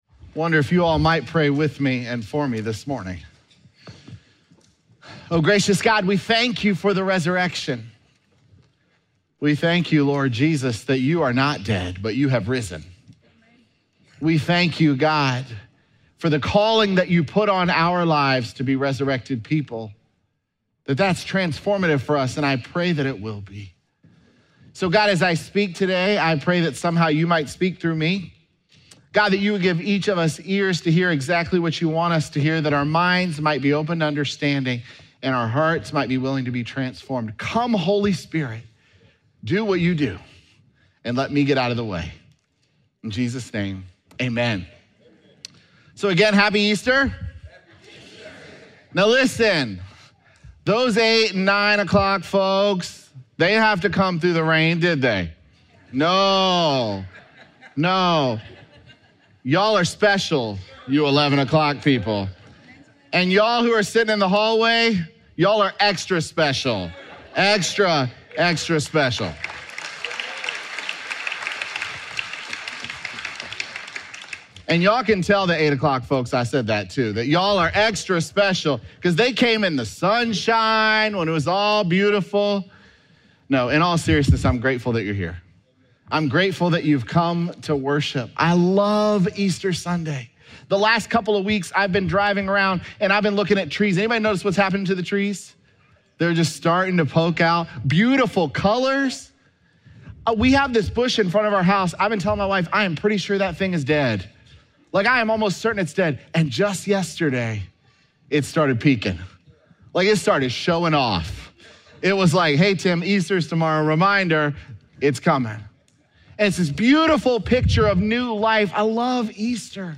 Sermons
Apr5SermonPodcast.mp3